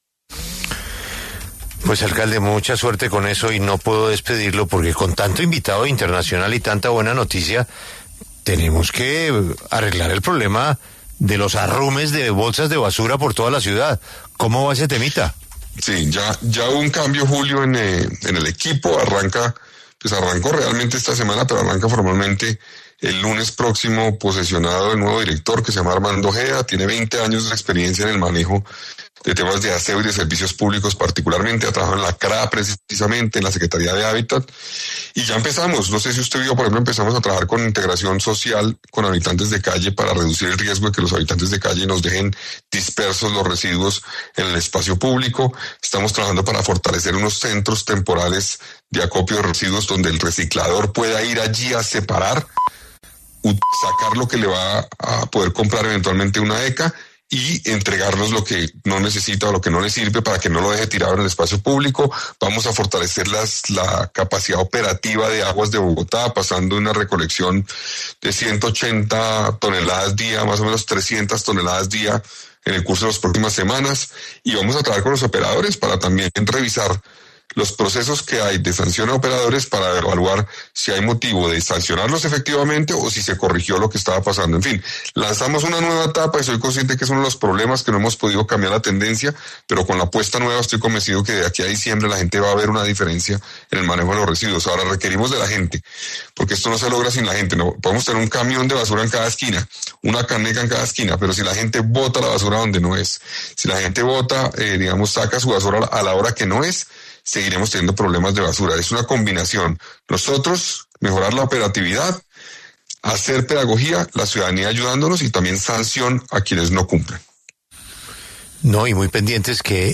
En los micrófonos de La W con Julio Sánchez Cristo, el alcalde Carlos Fernando Galán habló sobre la apuesta de la Alcaldía para mejorar la recolección de basuras en Bogotá y aseguró que los resultados se verán en el mes de diciembre.